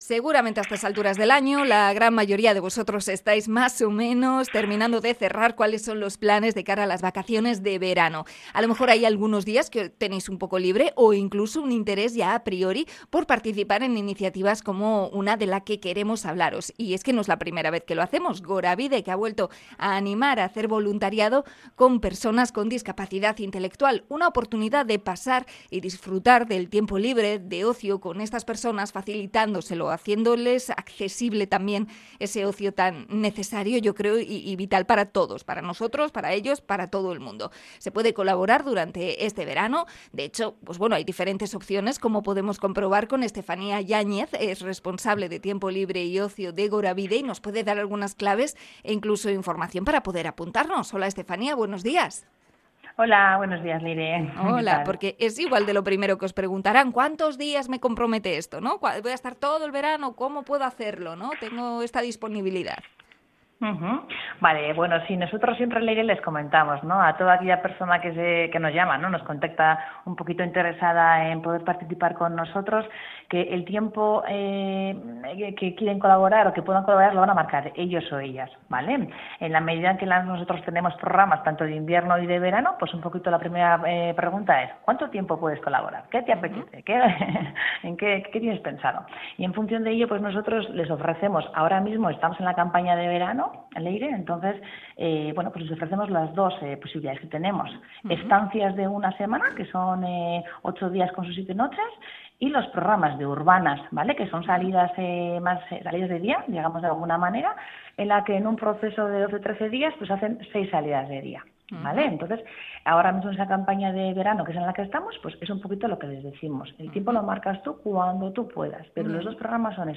Entrevista a Gorabide en su llamamiento de voluntariado